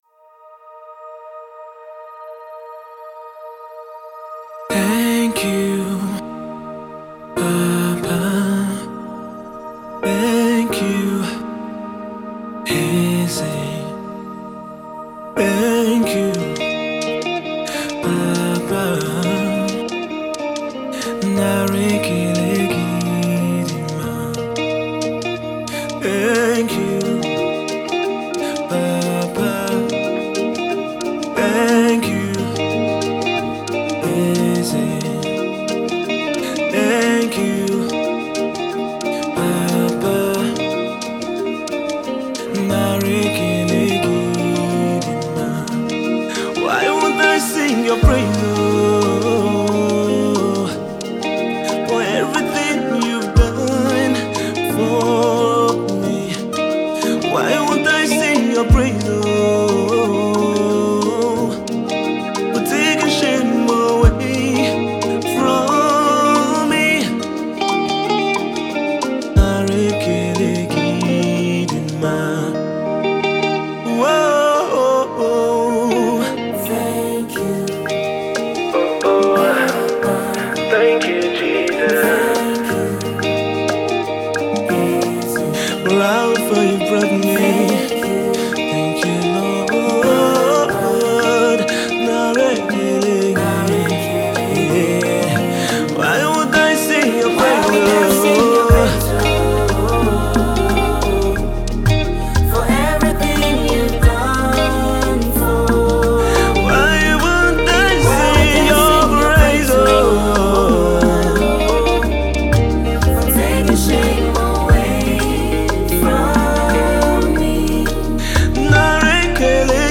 Nigerian gospel sensation
midtempo Afro-fusion tune with sweet melodies